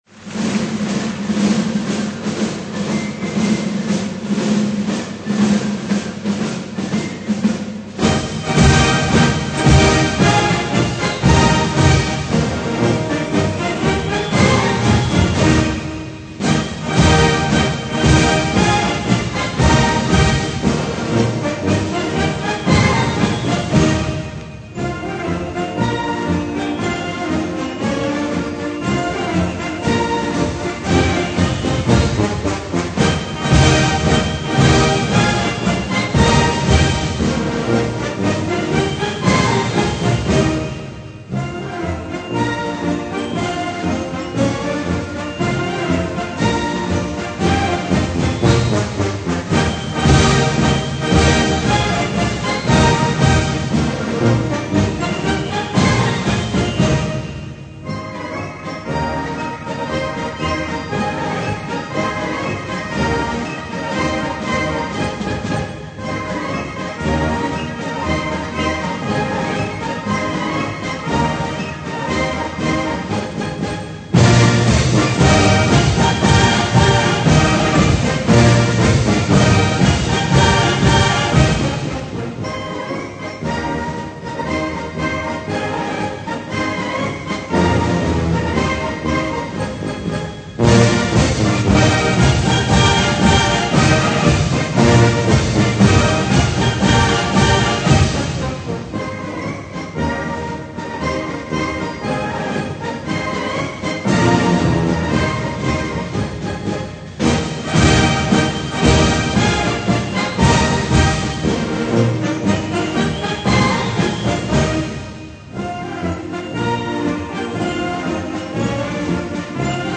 Marschmusik